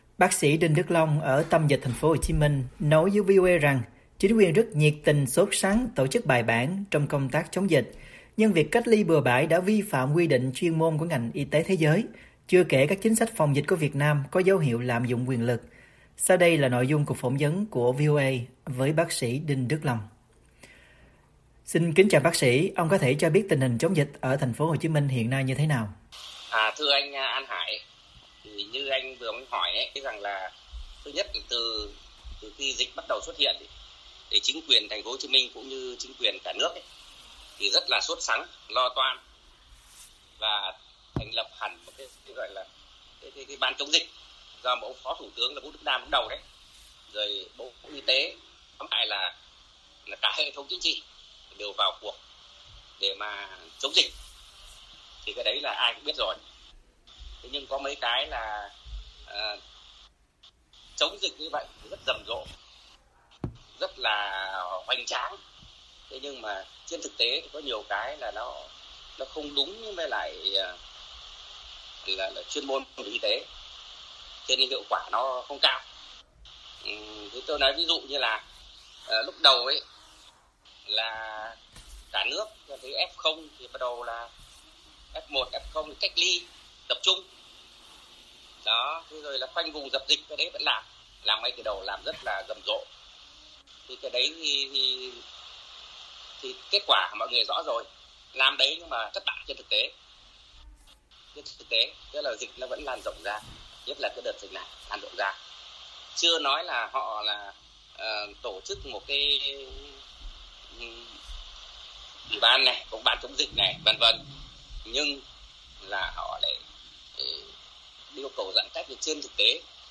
VOA phỏng vấn